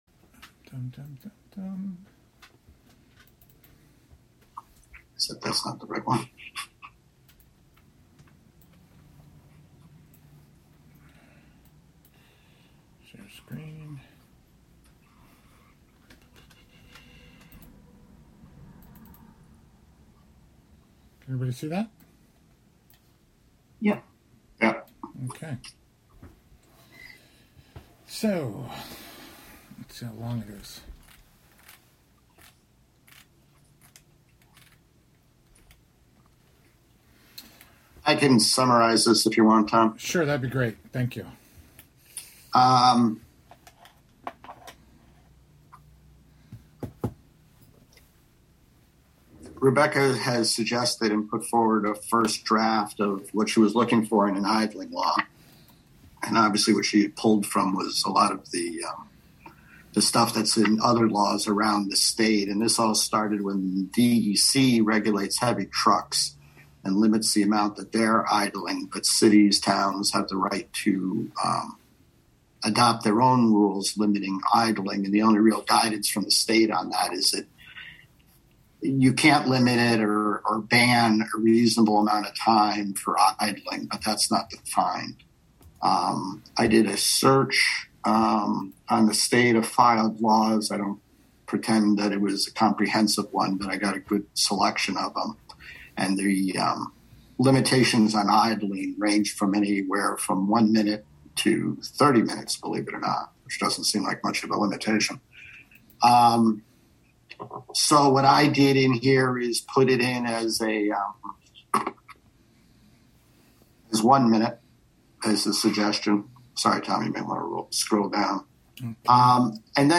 Live from the City of Hudson: Hudson Legal committee (Audio)